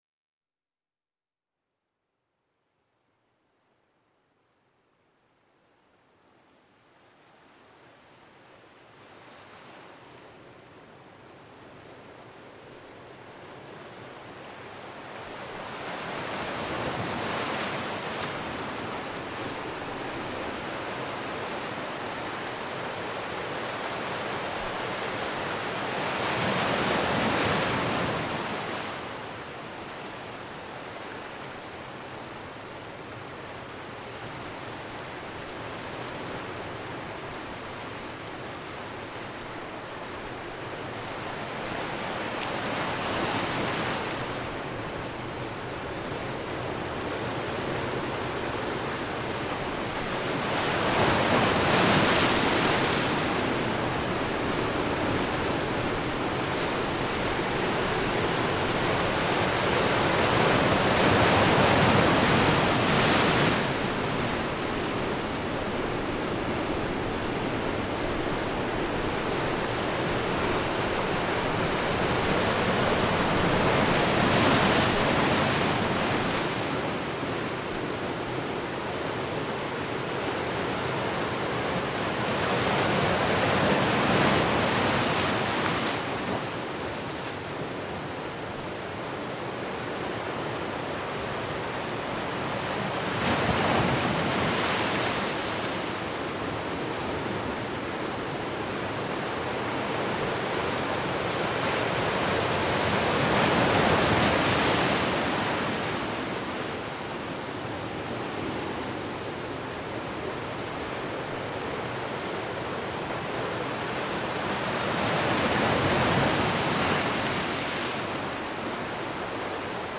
Pianeta Gratis - Audio - Natura - Varie
natura_nature06.mp3